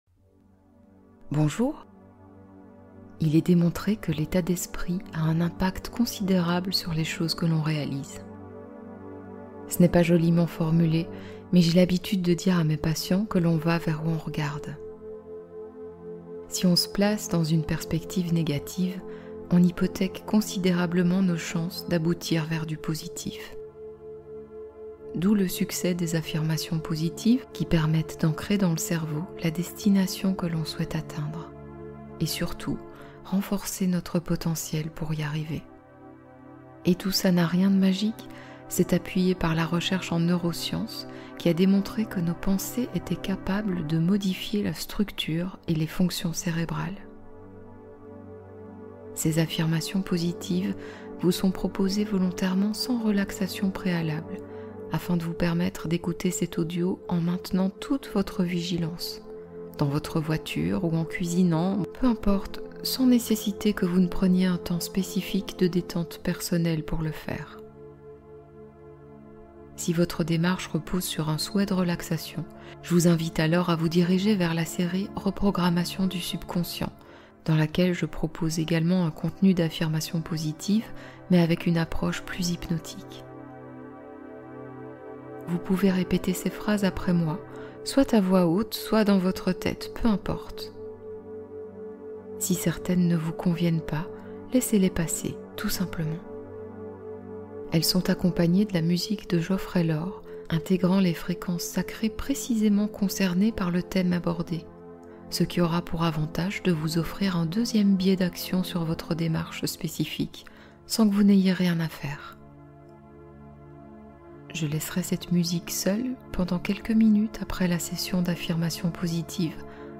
Dépendance affective : affirmations et fréquences guérissantes